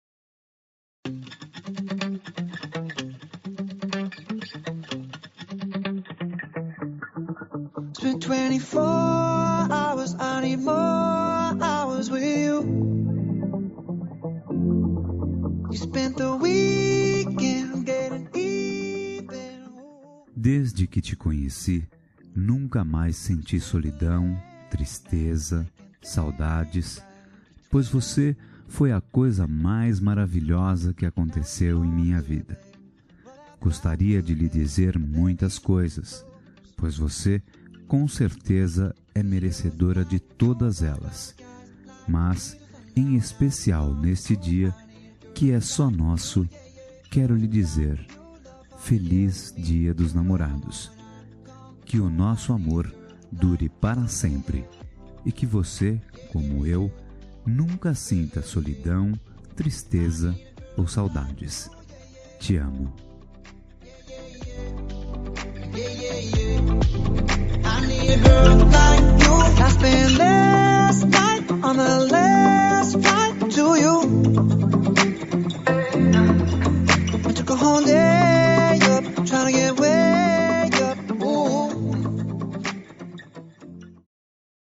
Dia dos Namorados – Para Namorada – Voz Masculina – Cód: 6874